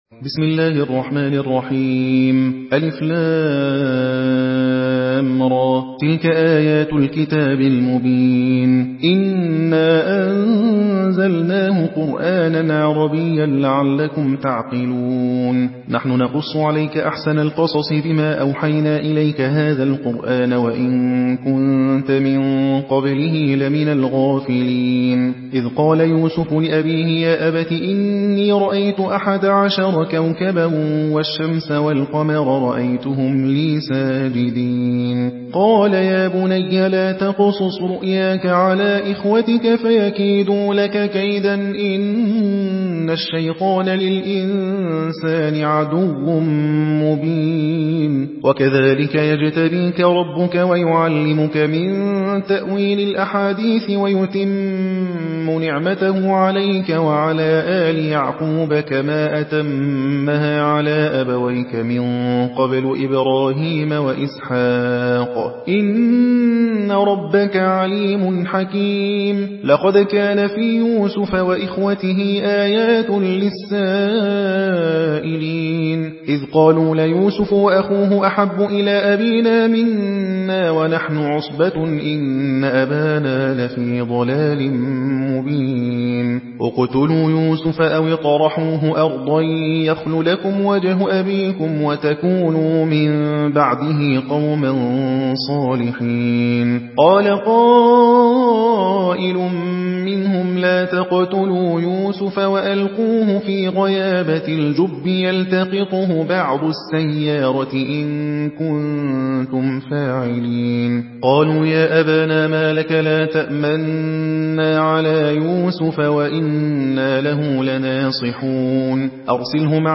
حدر حفص عن عاصم